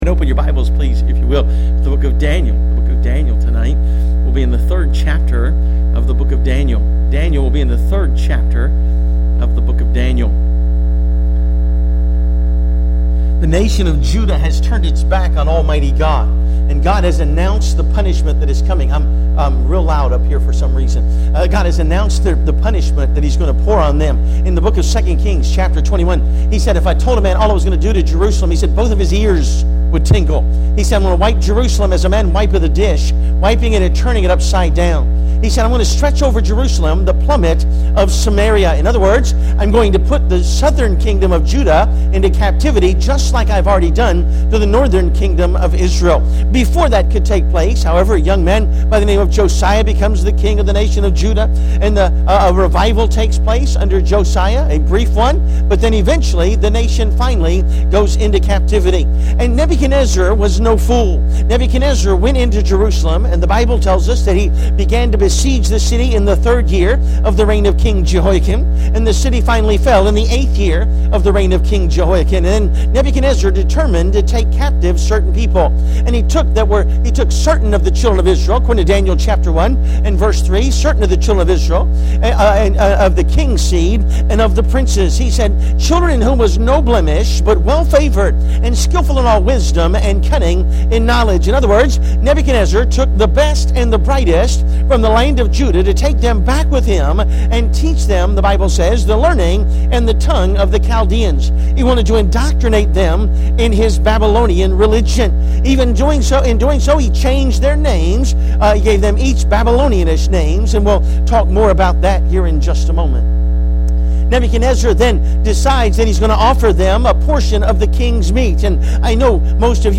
Service Type: Midweek Service